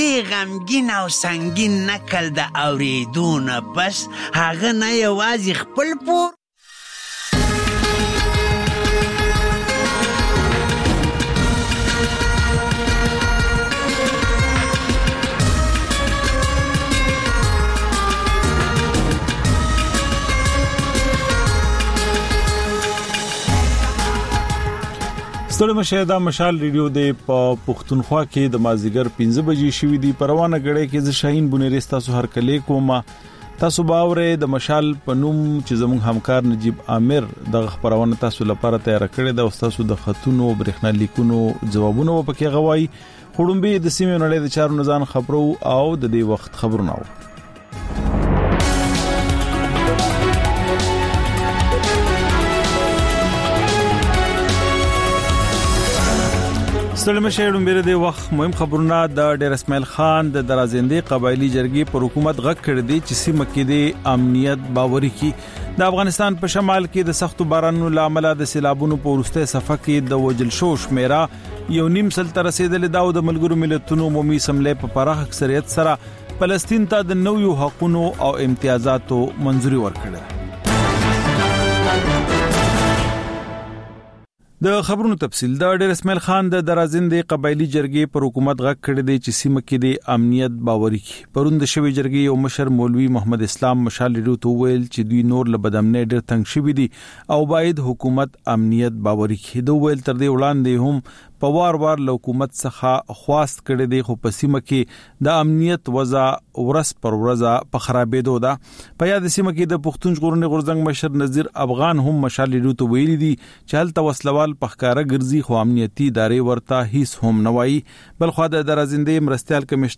د خپرونې پیل له خبرونو کېږي، بیا ورپسې رپورټونه خپرېږي. ورسره یوه اوونیزه خپرونه درخپروو. ځېنې ورځې دا ماښامنۍ خپرونه مو یوې ژوندۍ اوونیزې خپرونې ته ځانګړې کړې وي چې تر خبرونو سمدستي وروسته خپرېږي.